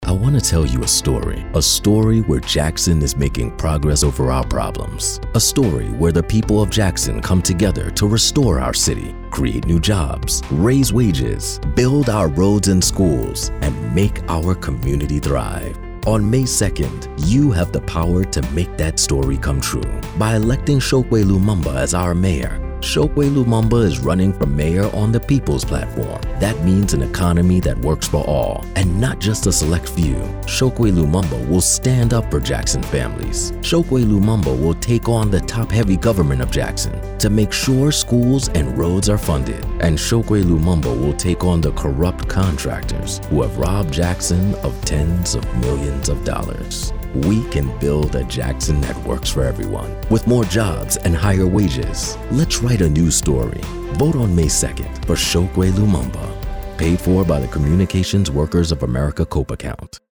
African American, Diverse Political Commercial Voice Over
Profound. Resonant. Real | Voiceovers